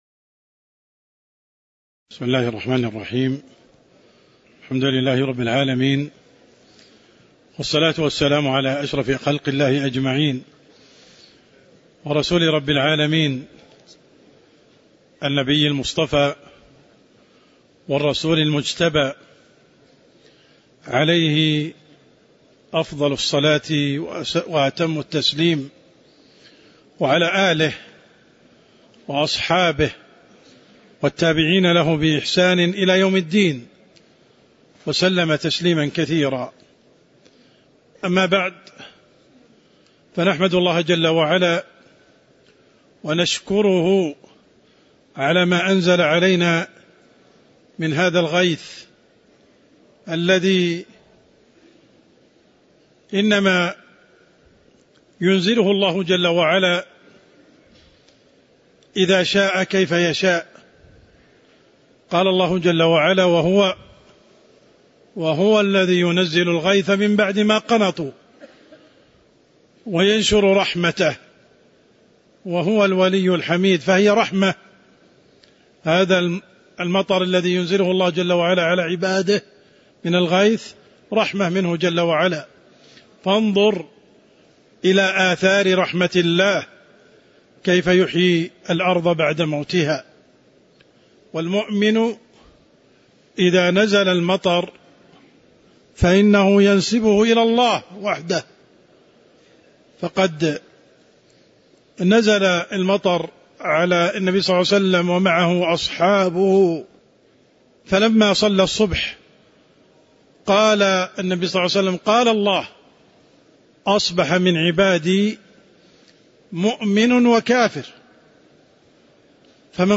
تاريخ النشر ٧ جمادى الآخرة ١٤٤٣ هـ المكان: المسجد النبوي الشيخ: عبدالرحمن السند عبدالرحمن السند باب المساقاة والمزارعة (010)